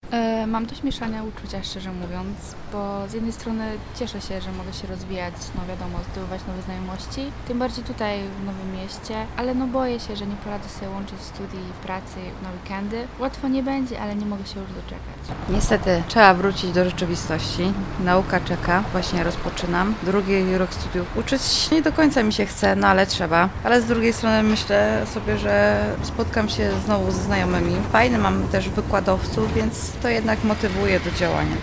To część z myśli jakimi podzielili się słuchacze wrocławskich uczelni wyższych, z którymi rozmawialiśmy.